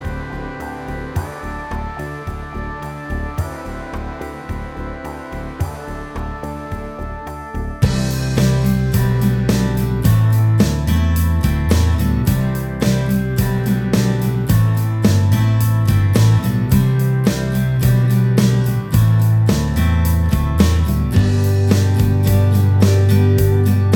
Minus Electric Guitar Indie / Alternative 5:08 Buy £1.50